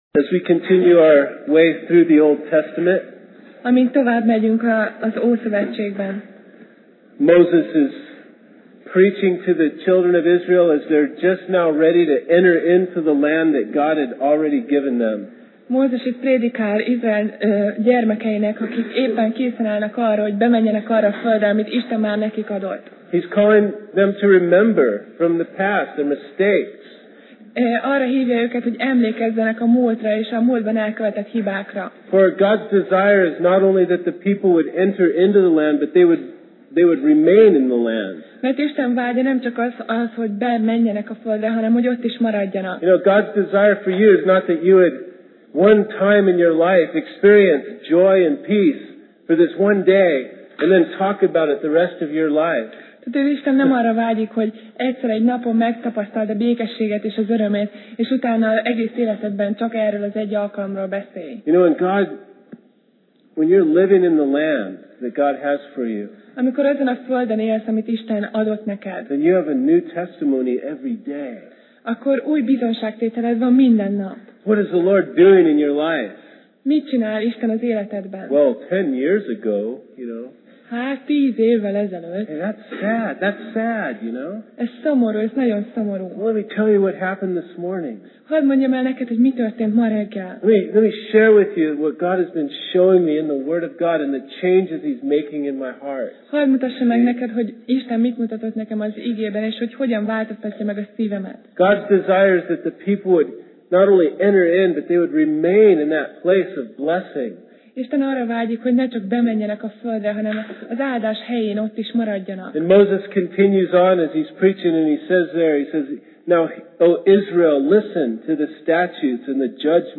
Passage: 5Mózes (Deut) 4:1-31 Alkalom: Szerda Este